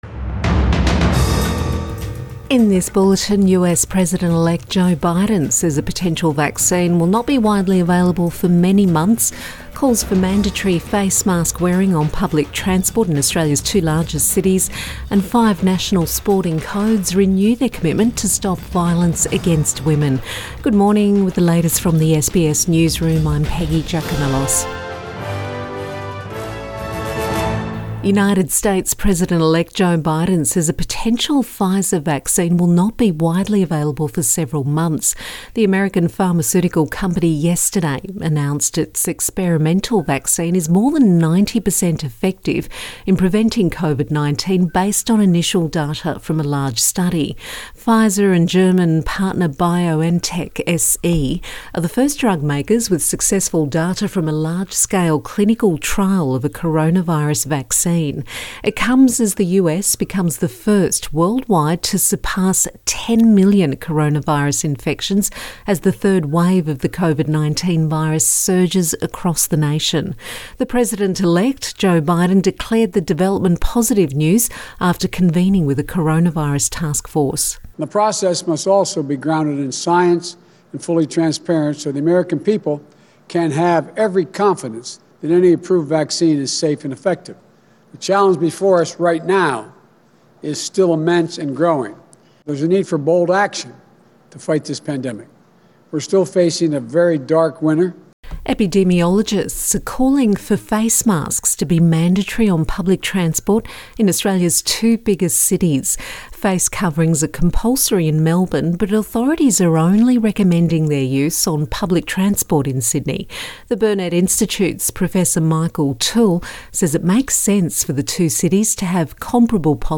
AM bulletin